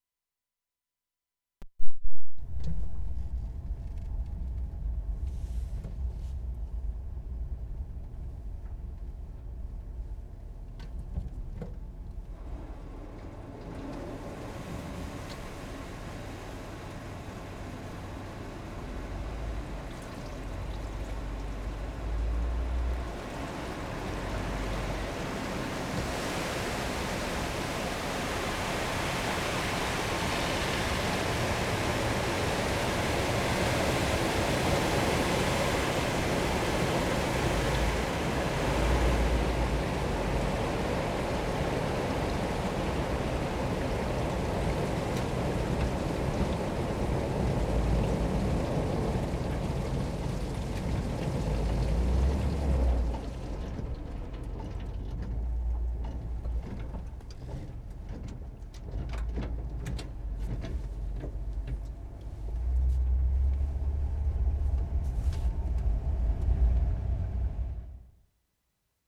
VANCOUVER ISLAND, NANAIMO March 16, 1973
1. HARMAC PULP MILL 1'30"
*3. Short sequence, basically an automated hose, to clean off employee's cars from smoke fallout and ash of the mill stack.